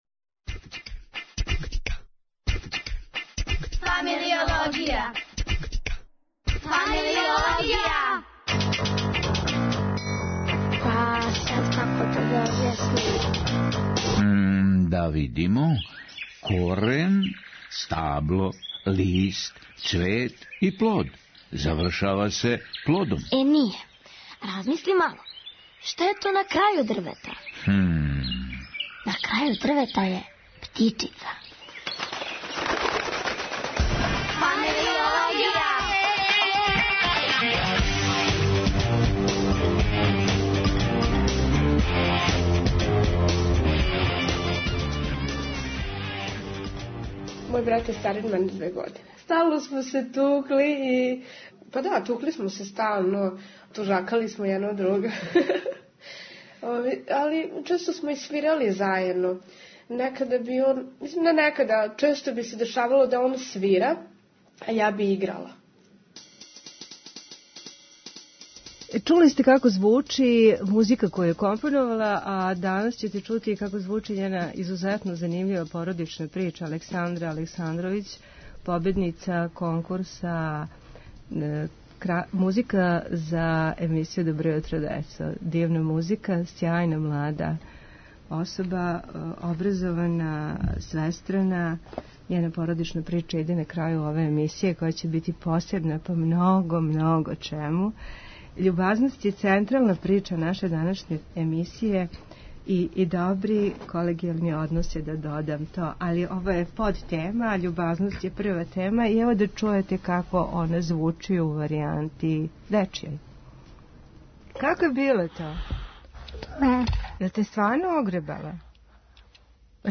Гошћа у студију